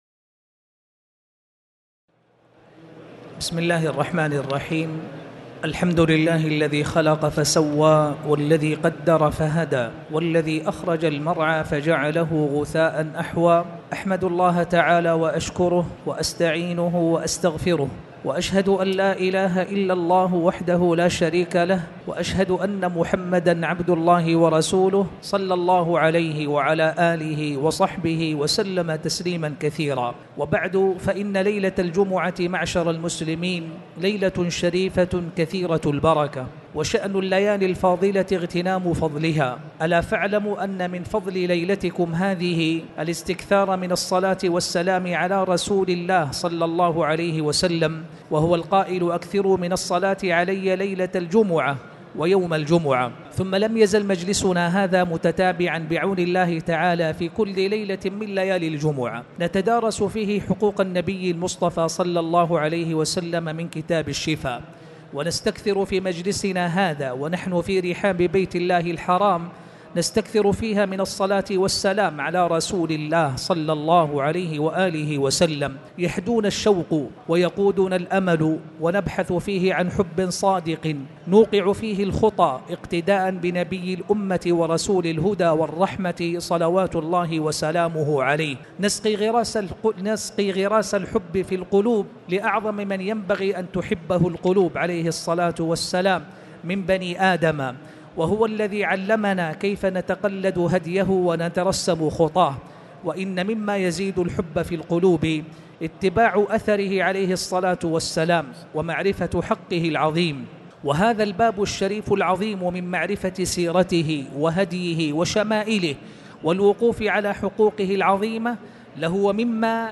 تاريخ النشر ٤ ذو القعدة ١٤٣٨ هـ المكان: المسجد الحرام الشيخ